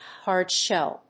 アクセントhárd‐shèll(ed)